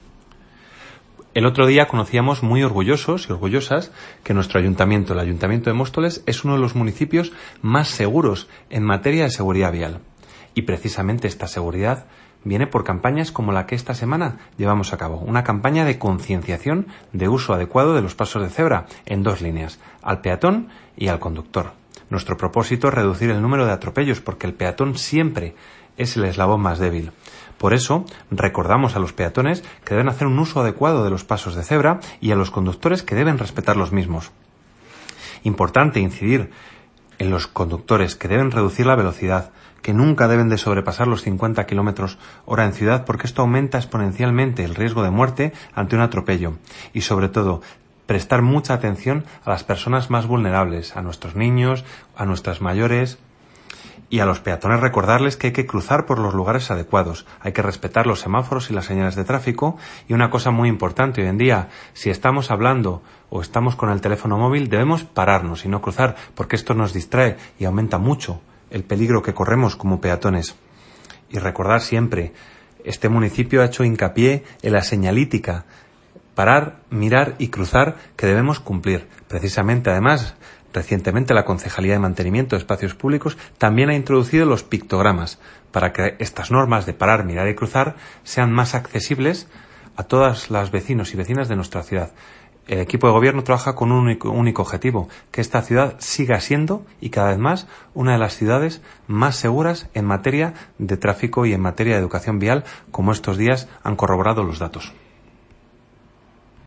Audio - Alejandro Martín (Concejal de Transición Ecológica y Seguridad) Sobre Pasos Peatones